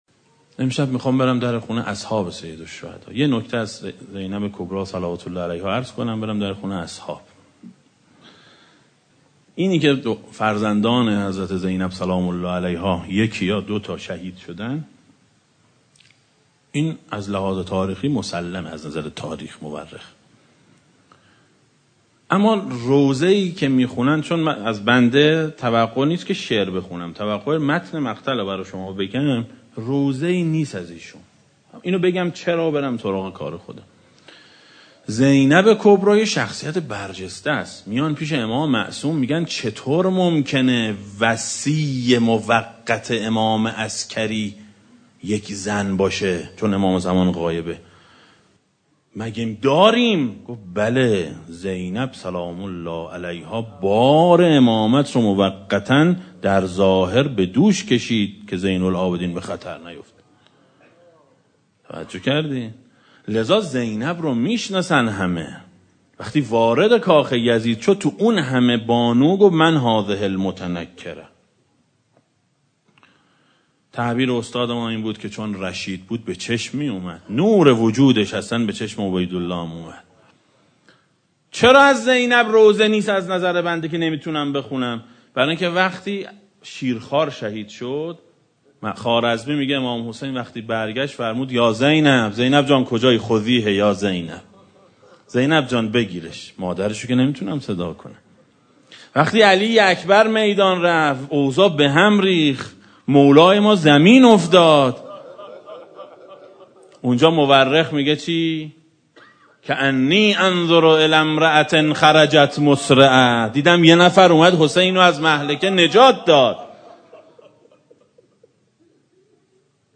دسته: امام حسین علیه السلام, روضه های اهل بیت علیهم السلام, سخنرانی ها
روضه شب چهارم محرم سال 1395 ـ مجلس دوم